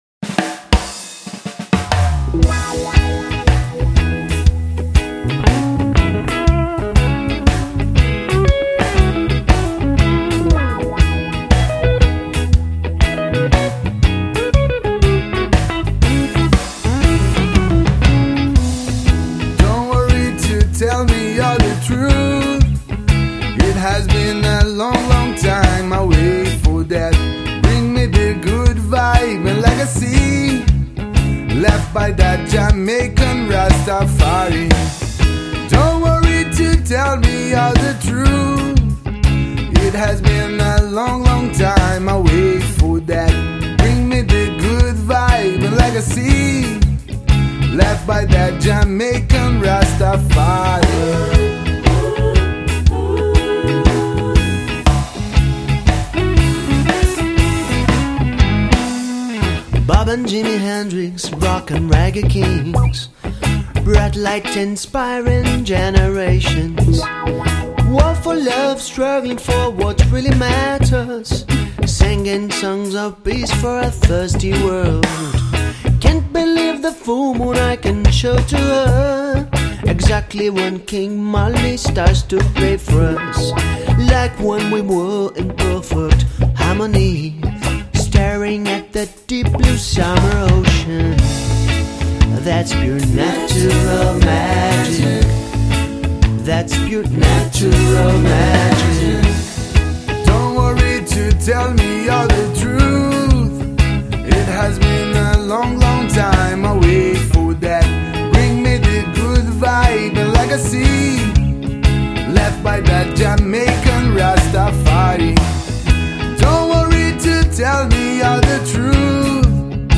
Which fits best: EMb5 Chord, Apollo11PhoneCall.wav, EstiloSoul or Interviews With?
EstiloSoul